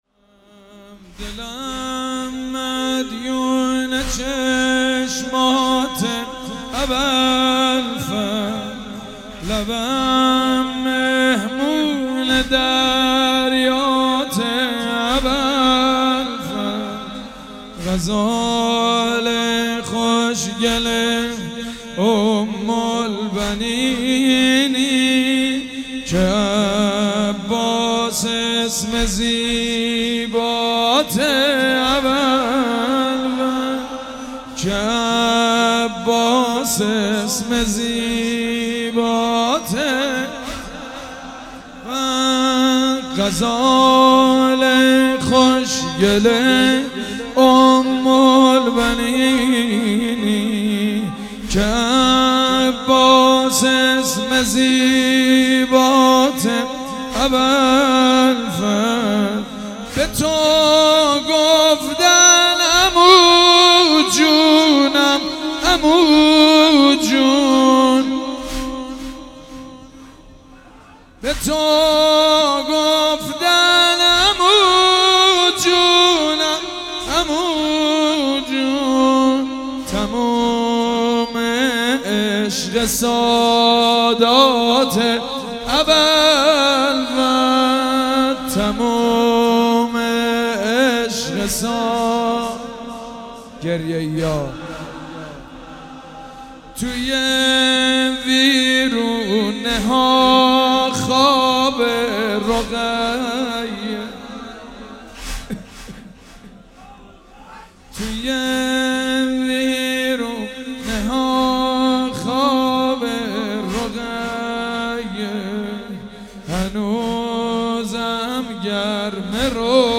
مداحی
در خمینی شهر